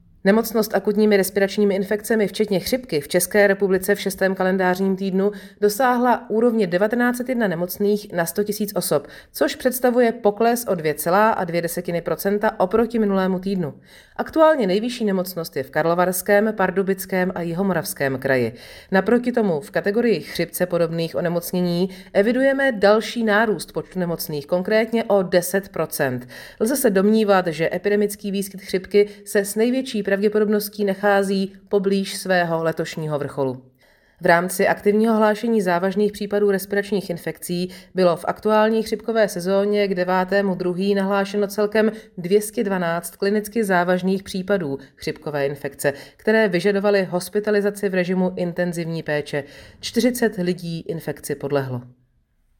Zpráva ve zvuku - 6.KT ARI ILI